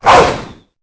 Cri_0836_EB.ogg